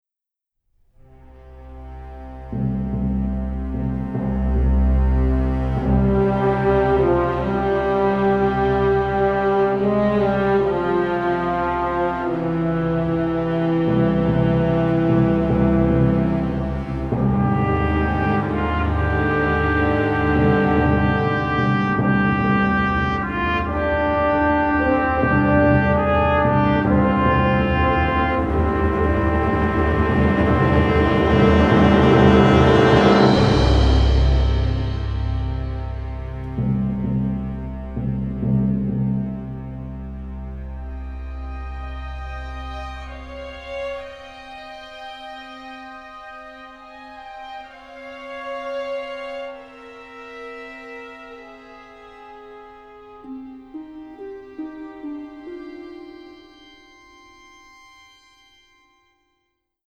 The score has been recorded in London